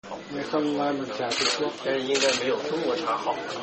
Trong bản tin truyền hình, lúc hai người thưởng trà, ông Trọng quay sang Tổng bí thư Tập và nói rằng “không ngon bằng trà Trung Quốc”.